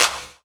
CLAP DM2.4.wav